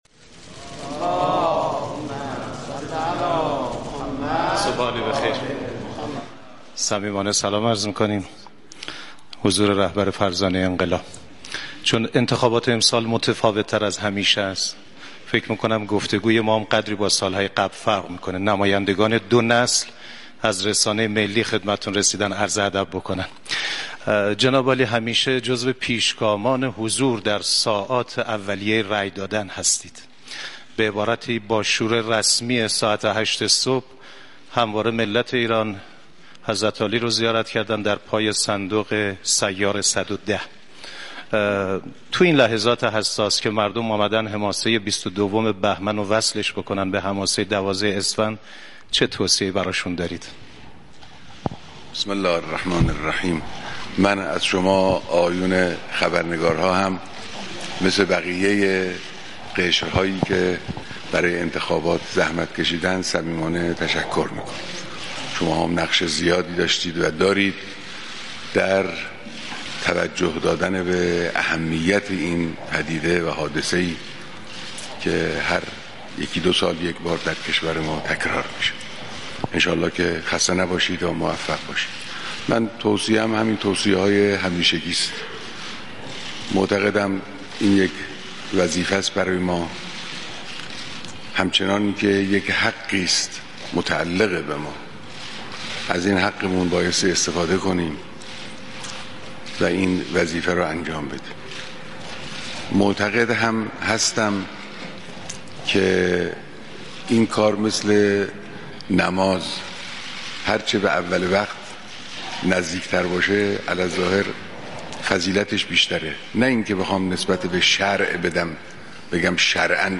سخنراني امام خامنه اي بعد از رأي دادن در انتخابات نهمين دوره مجلس شوراي اسلامي